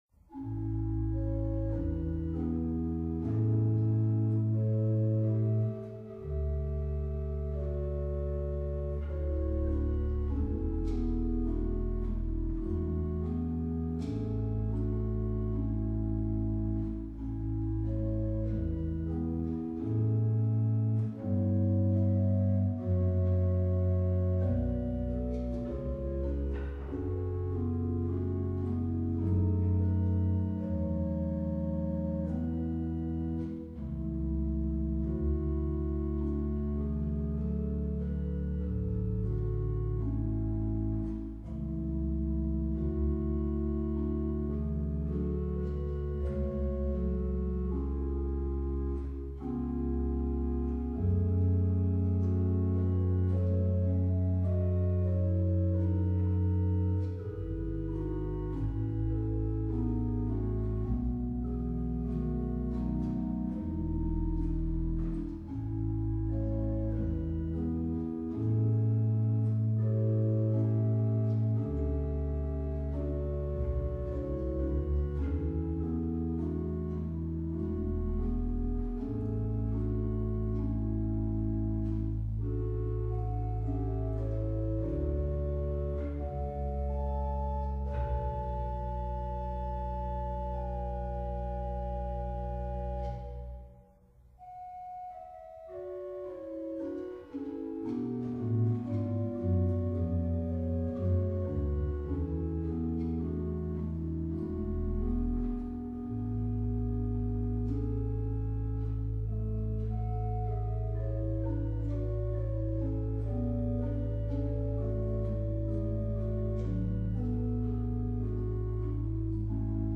Hörbeispiele der Orgel in Satow:
„Andante tranquillo“ aus: